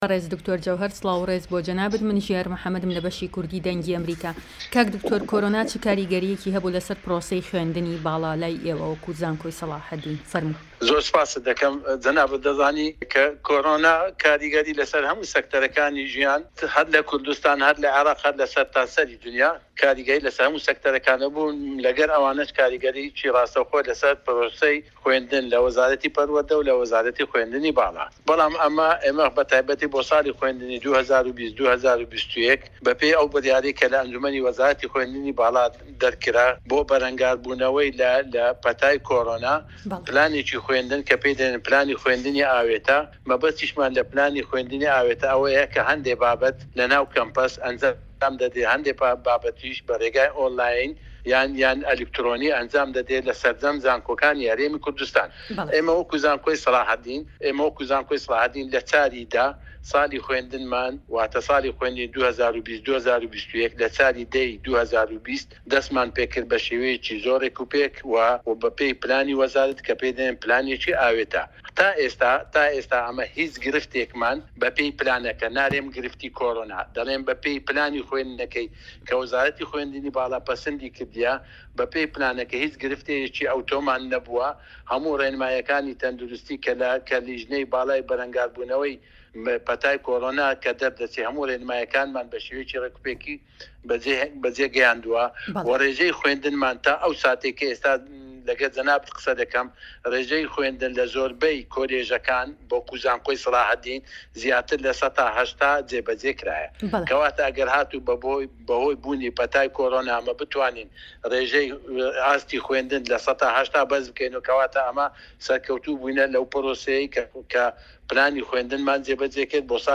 دەقی وتووێژەکەی